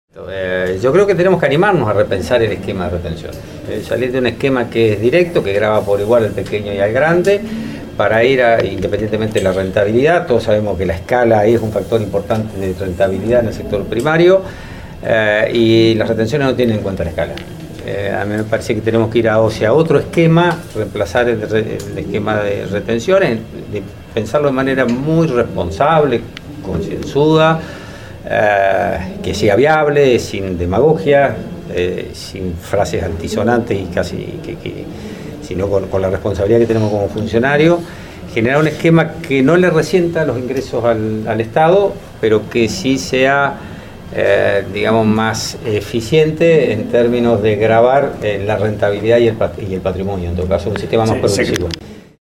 En la apertura del Primer Congreso Internacional de Maíz, el funcionario nacional sorprendió admitiendo el impacto negativo del gravamen.
"Tenemos que animarnos a repensar el esquema de retenciones. Un esquema que grava por igual al pequeño y al grande, independientemente de la rentabilidad. Todos sabemos que la escala es un factor portante en la rentabilidad en el sector primario, y las retenciones no tienen en cuenta la escala", sostuvo Bahillo en el discurso de apertura.